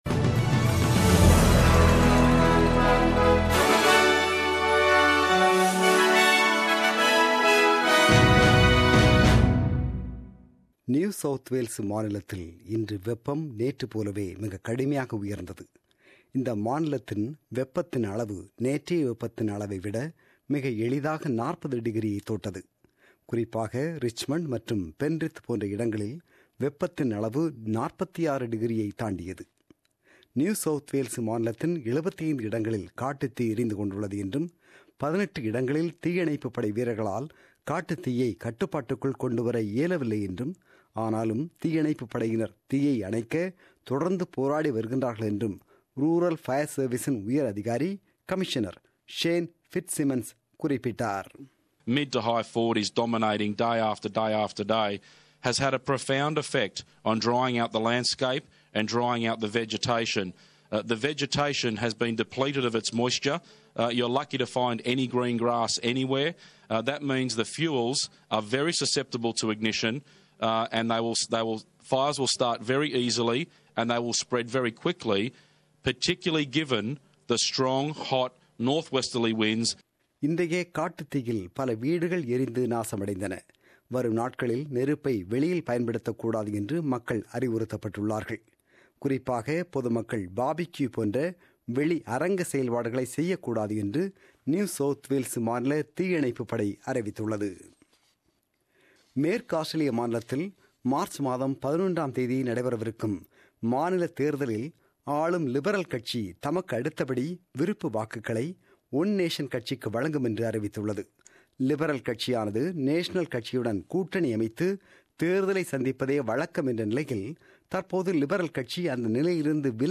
The news bulletin broadcasted on 12 February 2017 at 8pm.